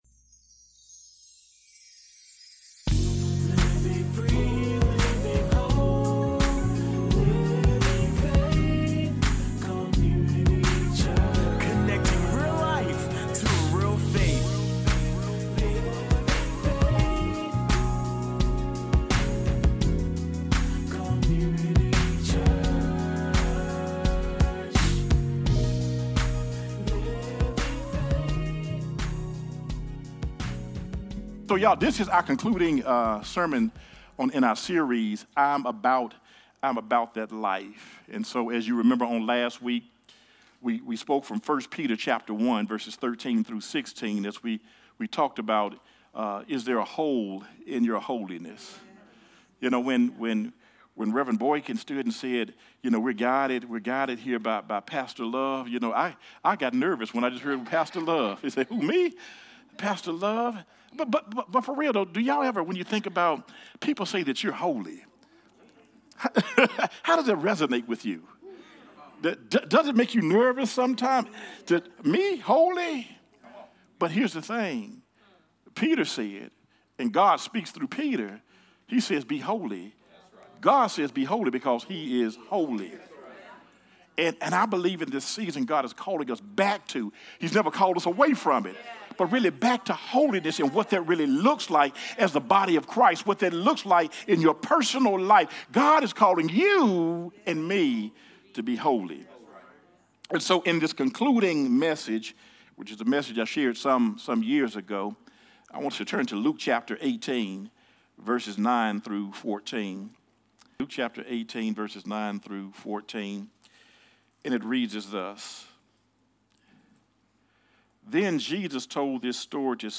Sermons | Living Faith Community Church